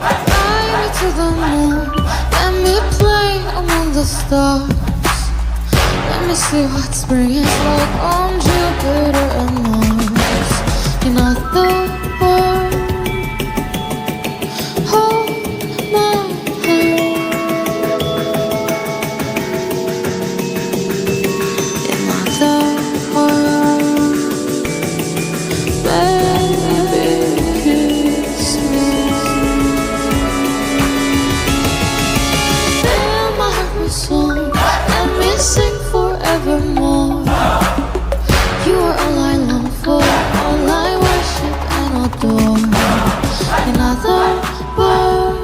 Kategori Elektronik